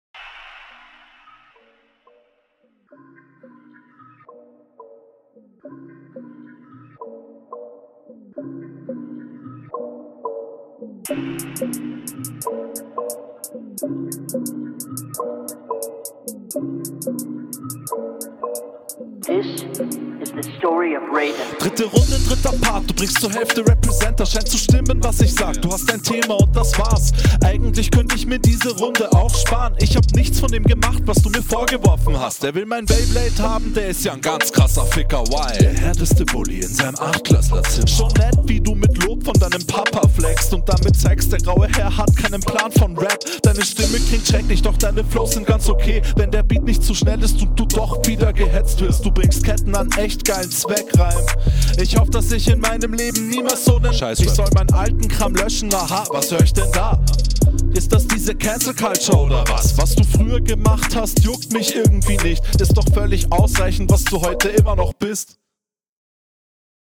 Auch tight gerappt auf nem Level mit hinrunde, musste beim ersten hören beim Antireim so …